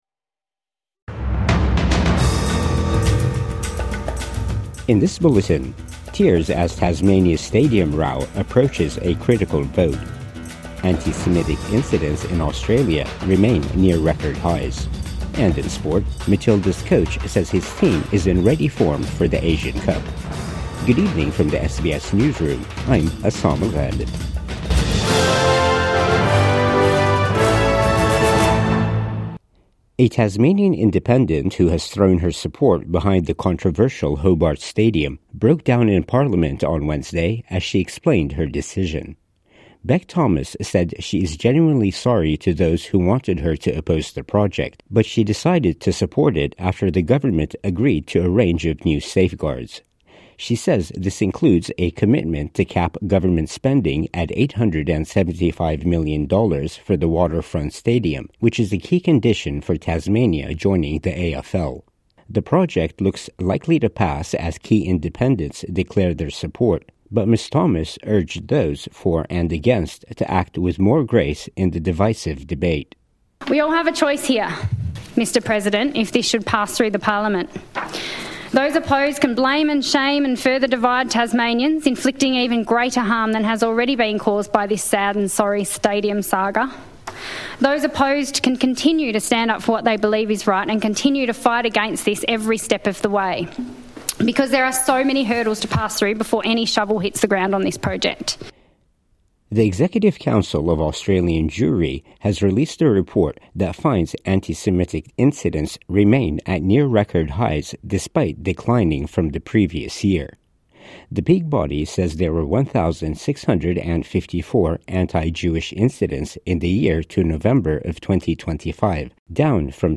Tears as Tasmania stadium row approaches critical vote | Evening News Bulletin 3 December 2025